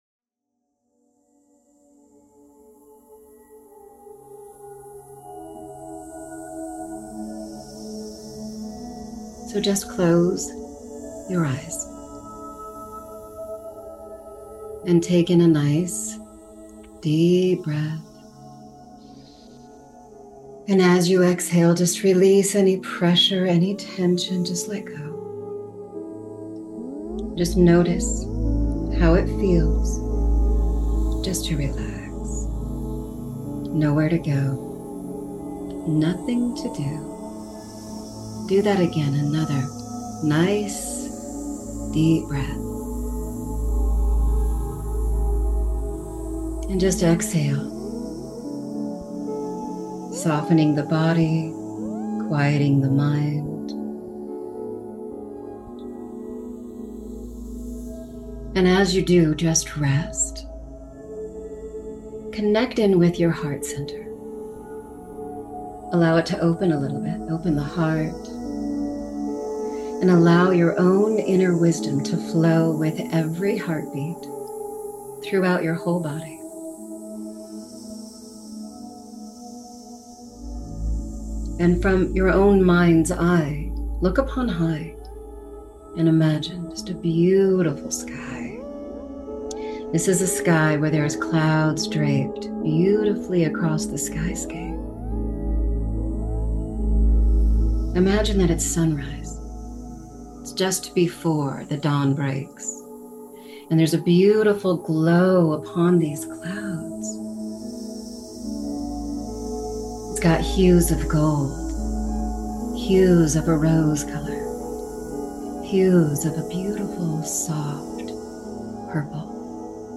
Guided Meditation with hypnosis to open the heart, mind, and spirit to the flow of healing with gratitude, prosperity, love, and so much more...